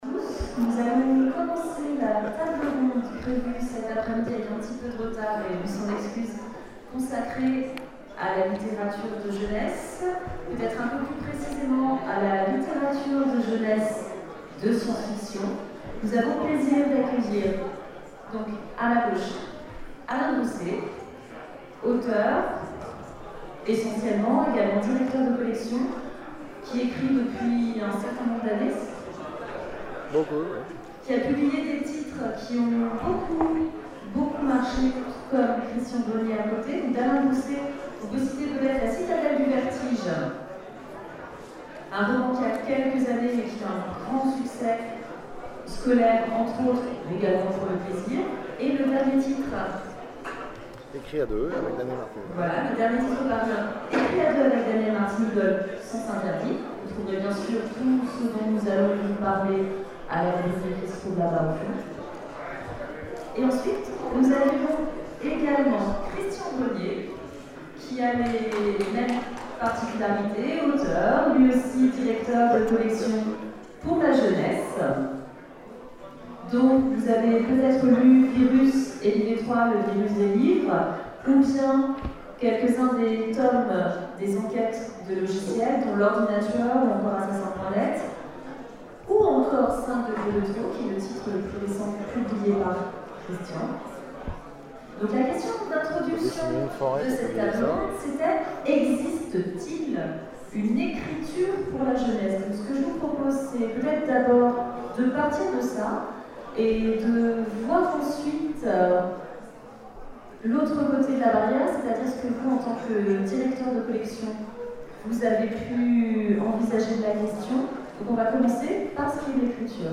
Convention 2012 : Conférence SF Jeunesse vs SF Vieillesse